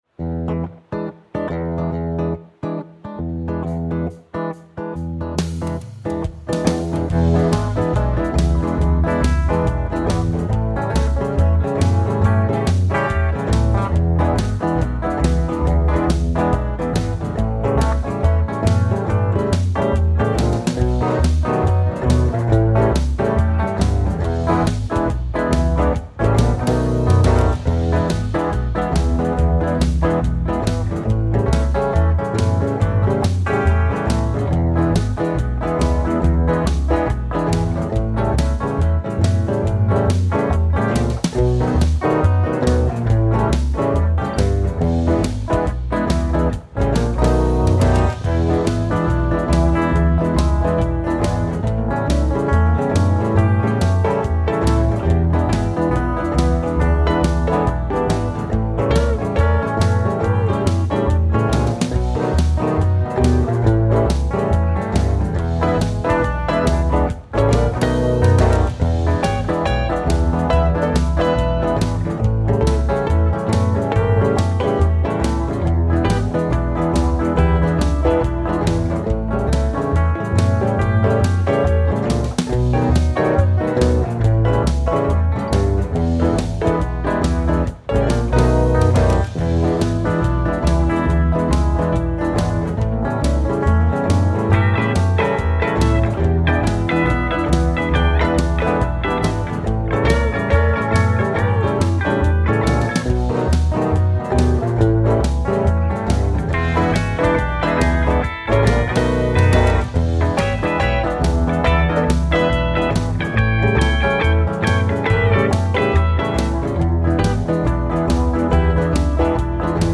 BASE BLUES